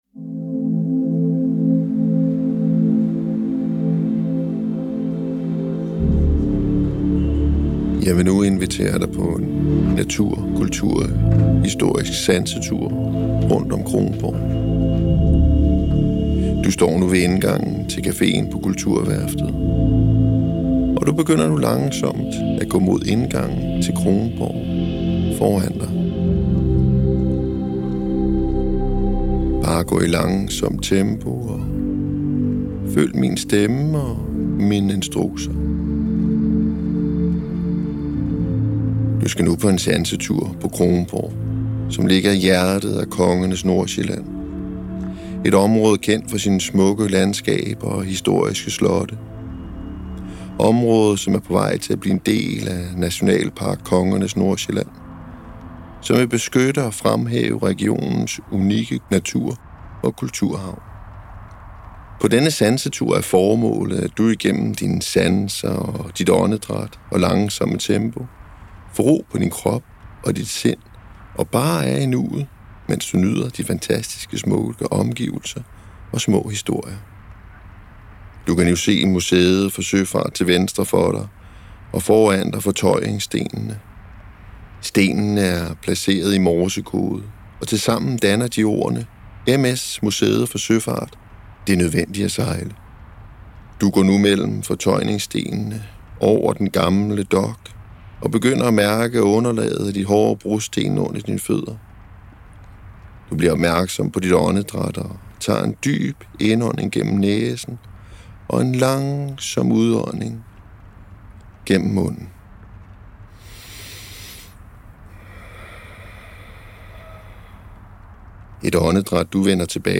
Stedsbestemt Sansetur // Audiowalk.